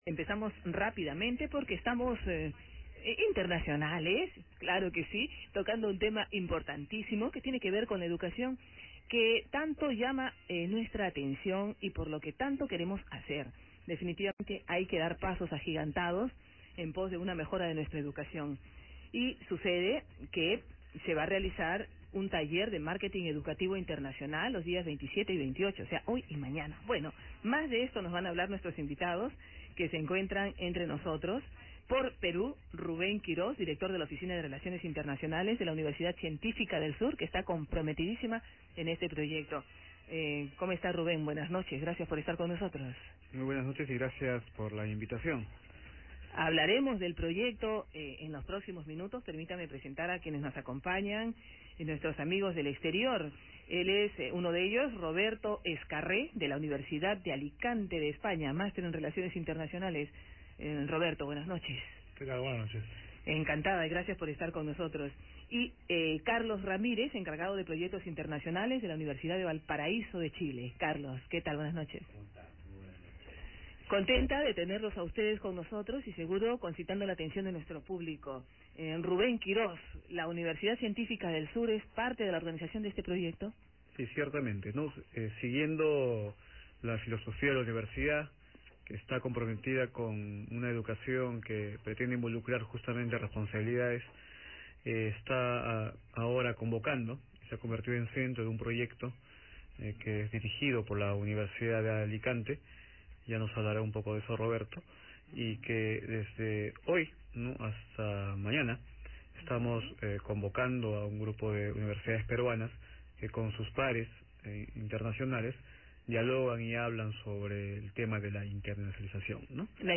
Entrevista realizada con los expositores del workshop
Entrevista Radio Nacional.mp3